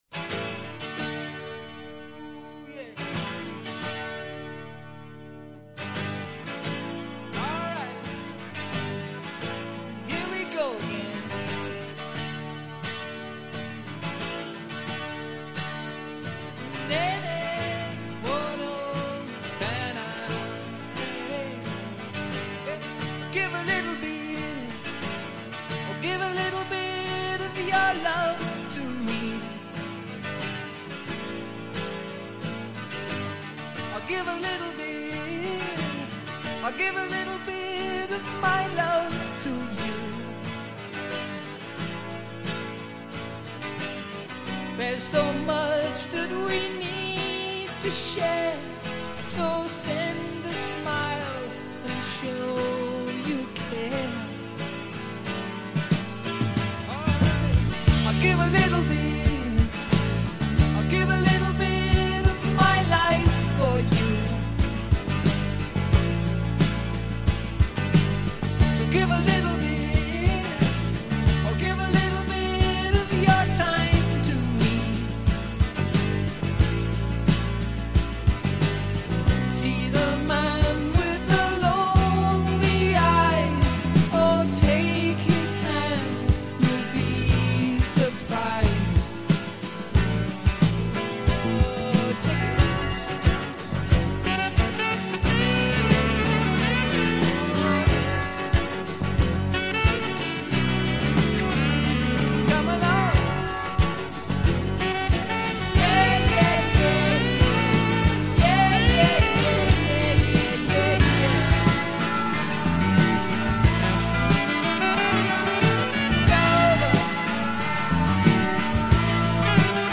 – Very nice usage of stereo